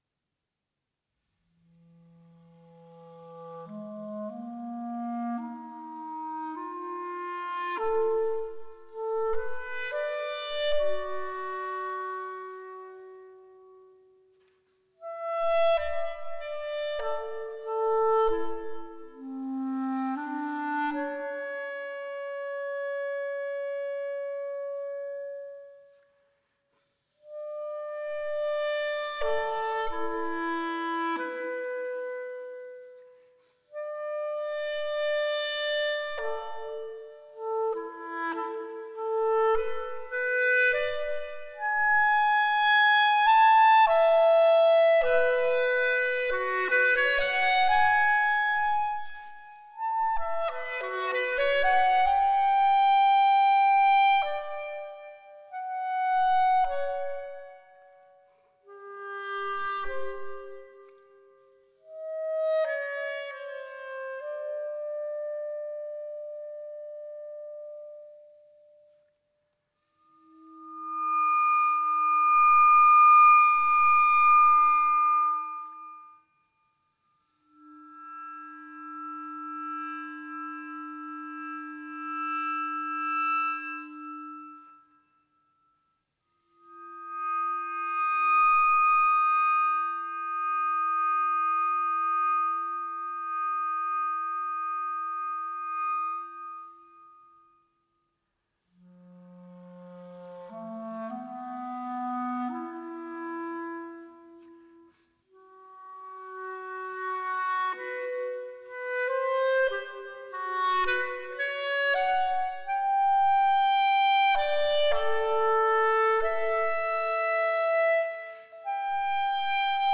Камерно-инструментальная музыка